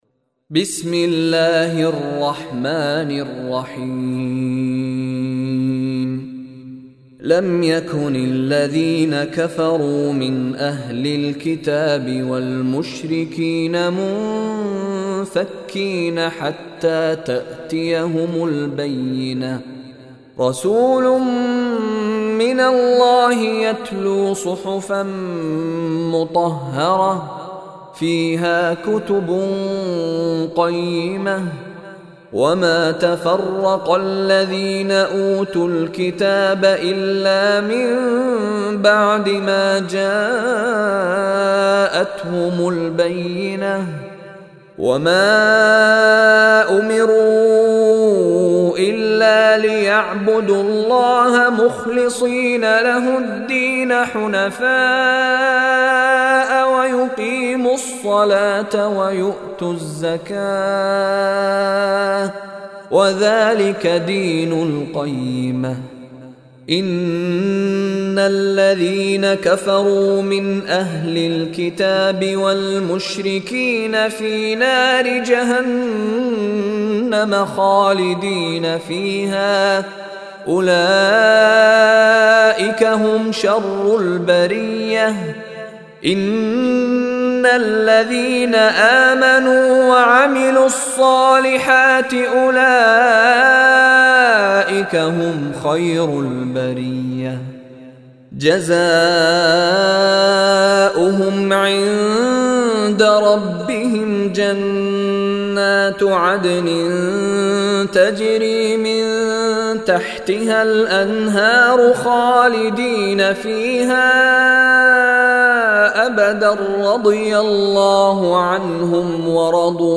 Surah Al-Bayyina Recitation by Mishary Rashid
Surah Al-Bayyina is 98th chapter / surah of Holy Quran. Listen online and download beautiful Quran tilawat / Recitation of Surah Al-Bayyina in the voice of Sheikh Mishary Rashid Alafasy.